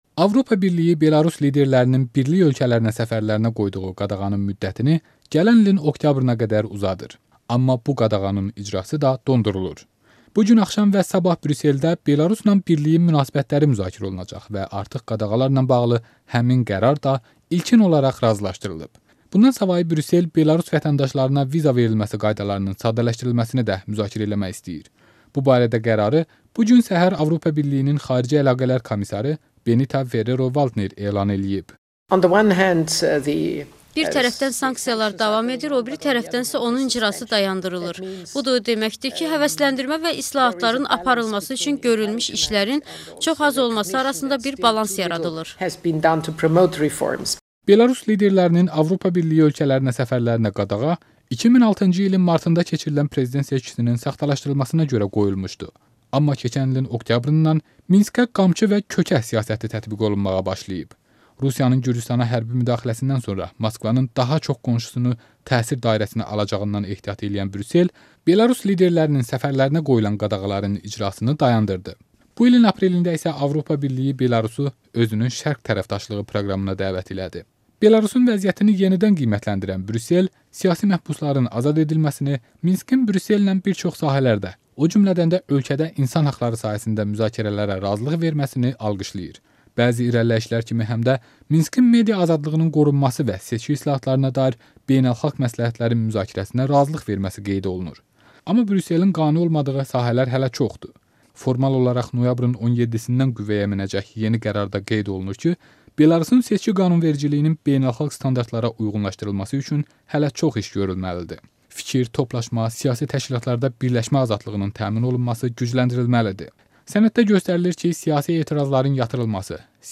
Brüsseldən reportaj